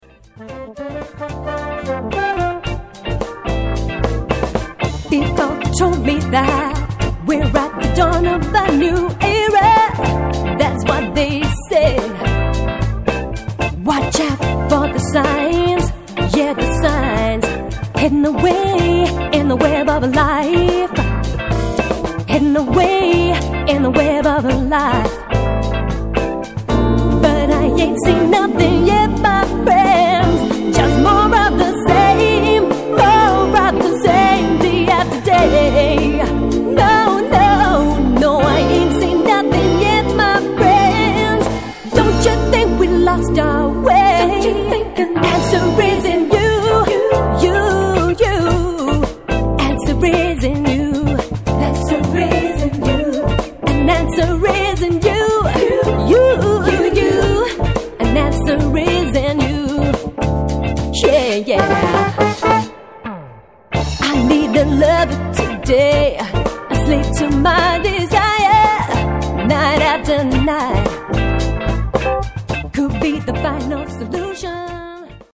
These songs are pre-production demos.
This song is a Funky number.